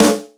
• Clear Snare Single Hit D Key 91.wav
Royality free steel snare drum sound tuned to the D note. Loudest frequency: 1787Hz
clear-snare-single-hit-d-key-91-HSV.wav